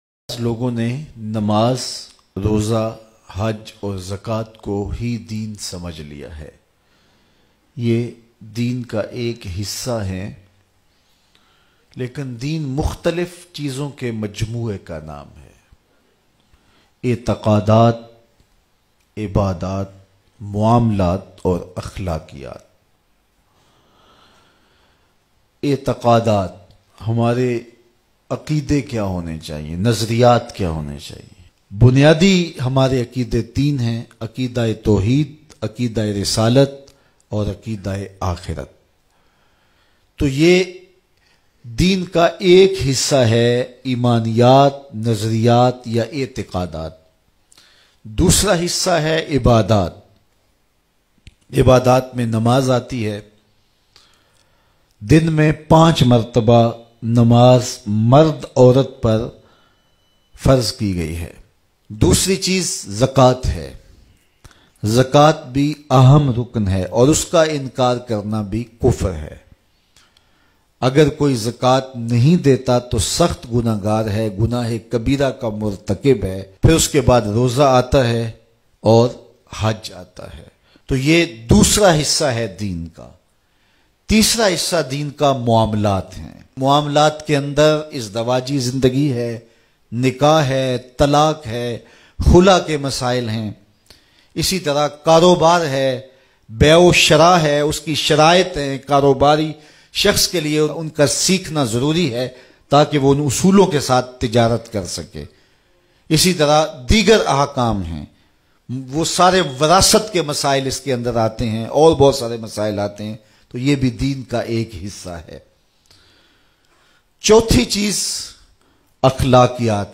Dunyawi Kam Kb Deen Bante Hain Bayan MP3